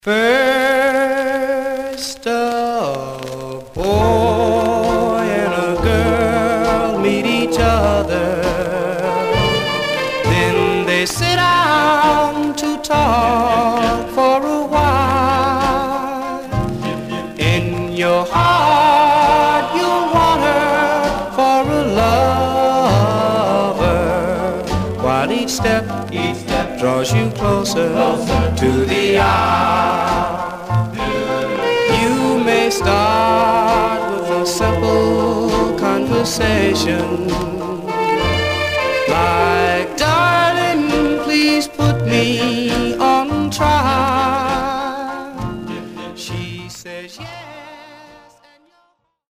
Some surface noise/wear
Mono
Male Black Groups